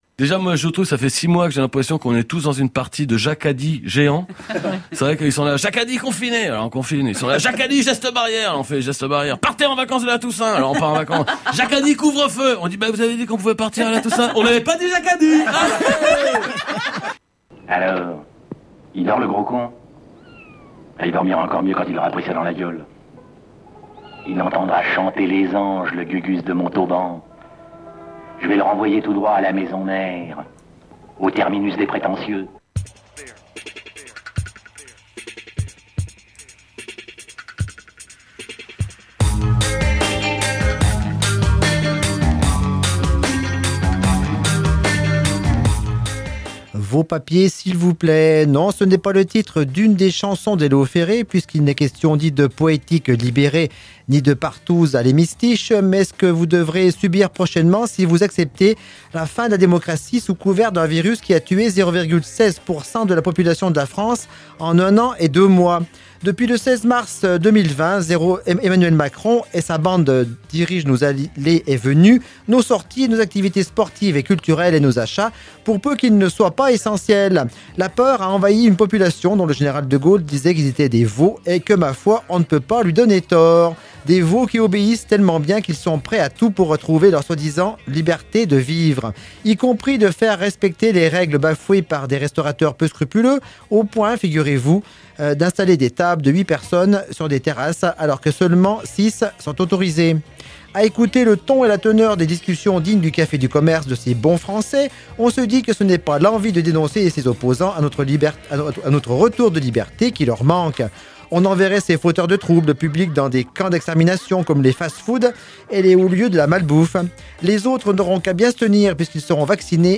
Radio B, la radio locale de Bourg-en-Bresse et des Pays de l'Ain